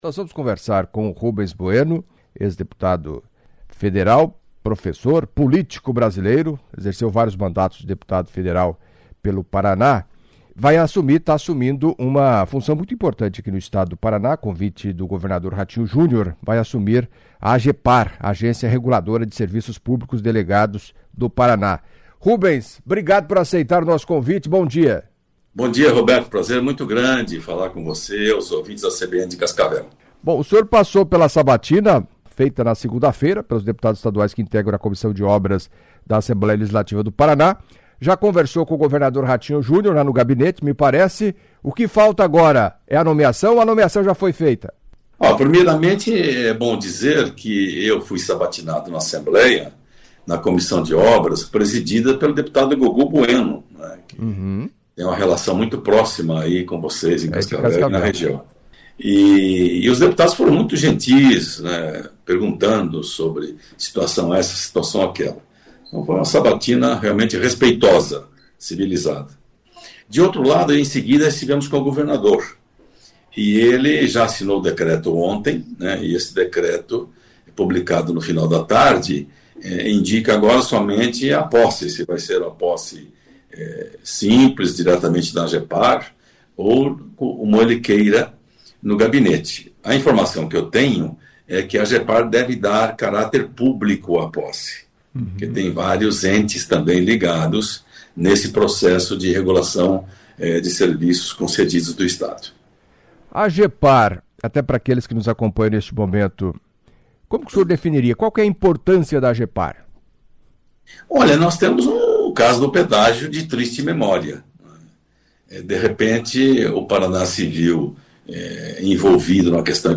Em entrevista à CBN Cascavel nesta quinta-feira (05) Rubens Bueno, ex-deputado federal, falou do desafio ao assumir o comando da Agepar.